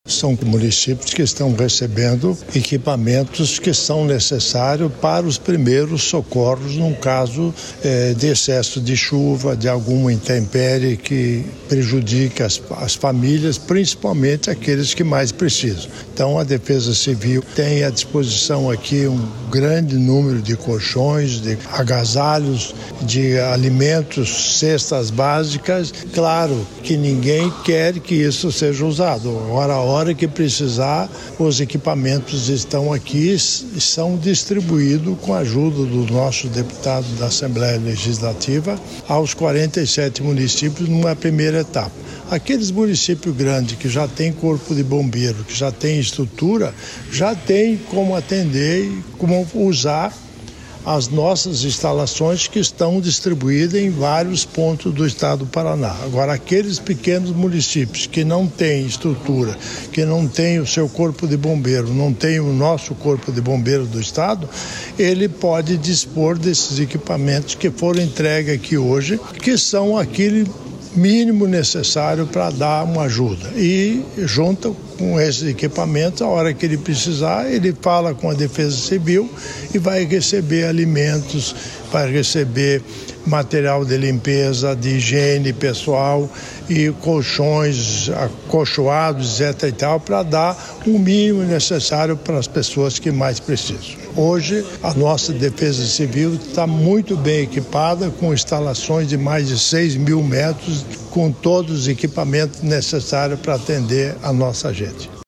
Sonora do governador em exercício Darci Piana sobre a entrega de equipamentos para Bombeiros e "kits de resposta" a eventos climáticos para municípios | Governo do Estado do Paraná